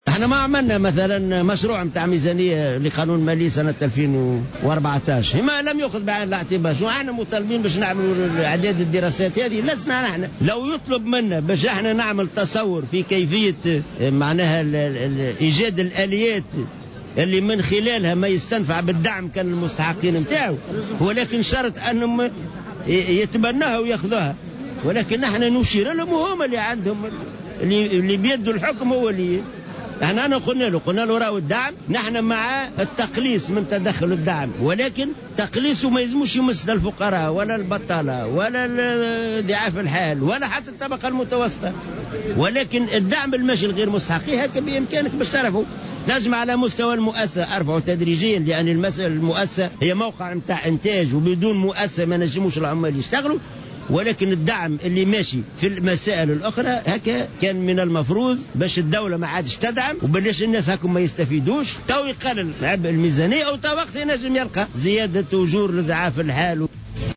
أكد الأمين العام للإتحاد التـُونسي للشـُغل حسين العباسي العبــّاسي اليوم الاربعاء 19 فيفري 2014 لدى افتتاحه مؤتمر الإتحاد الجهوي للشغل بالمهدية أن الاتحاد دعا إلى التقليص في الدعم المتوجه لغير مستحقيه شرط ألا يضر ذلك بالطبقة الفقيرة وبضعاف الحال.